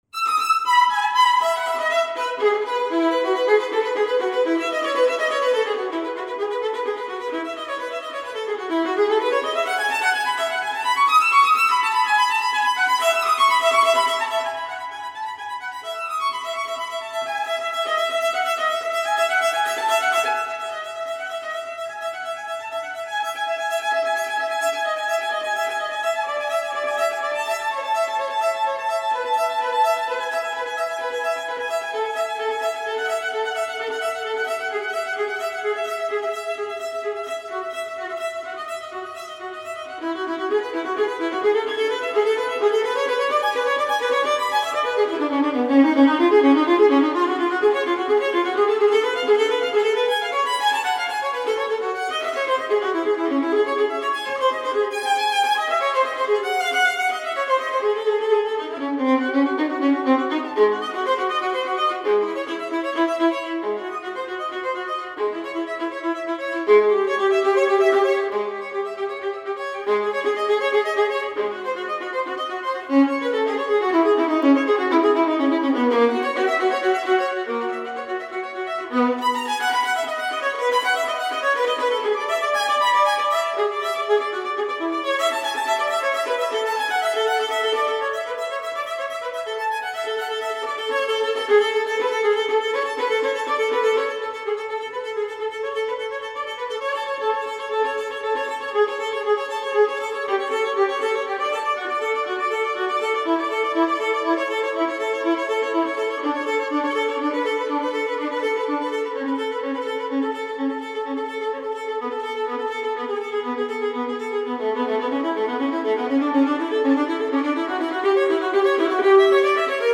Baroque Music for the Violin
Lucerne Baroque